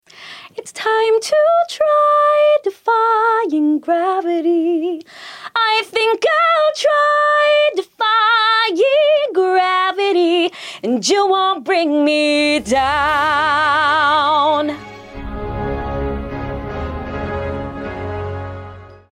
🎤 Ever wondered how singers move effortlessly from rich low notes to soaring highs?
vocal demo